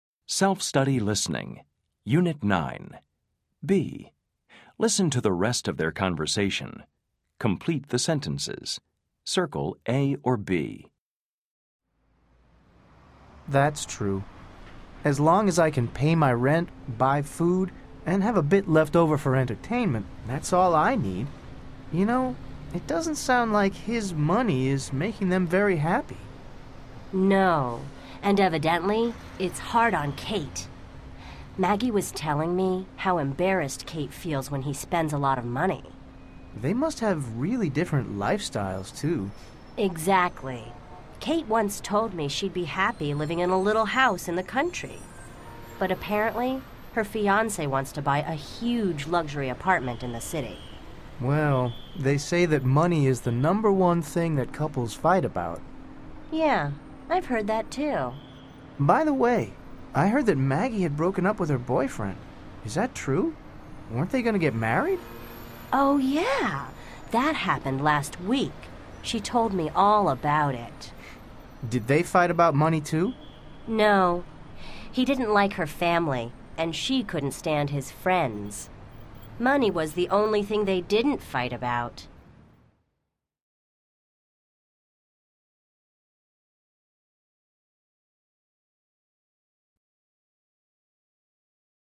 American English
The Touchstone Class Audio Cassettes include the Getting Started, Speaking Naturally, and Building Vocabulary exercises, as well as grammar, conversation strategies, and listening activities, all recorded by fluent native and non-native speakers of English.